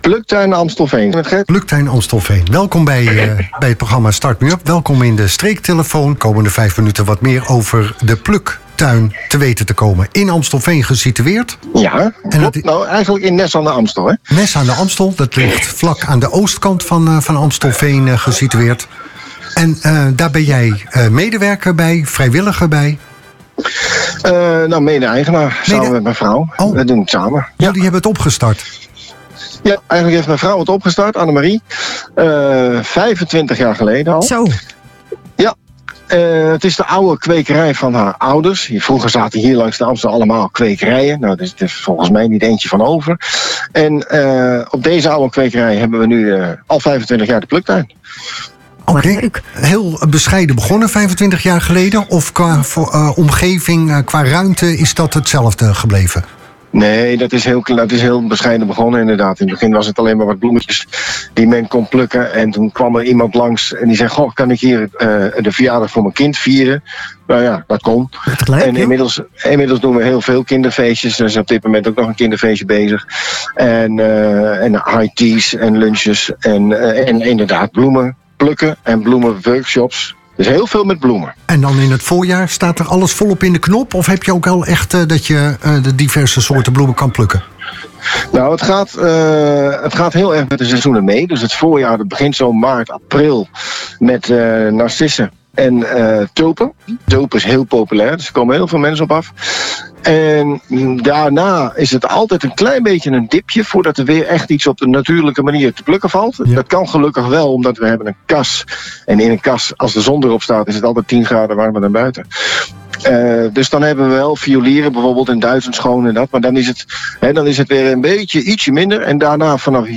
Streektelefoon: Pluktuin open op Amstellanddag: ontdek het groene hart van Nes